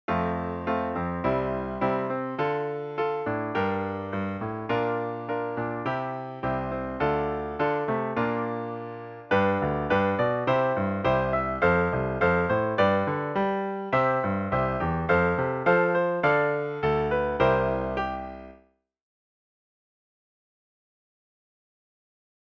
Each one is in two parts, where the first part acts as a verse progression, and the second one works as a chorus. The end of each chorus progression gives you a way back to the verse.
3. From F major to C major: F  C  Dm  Gm  Bb  C  Dm  Bb  |G  C  F  G  C  F  Dm  C [